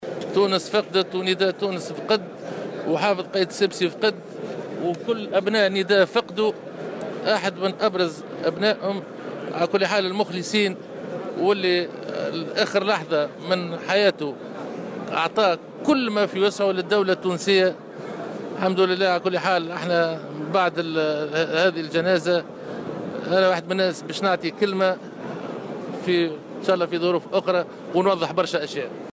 حافظ قائد السبسي في جنازة الفقيد سليم شاكر : سأوضح لاحقا عديد الأشياء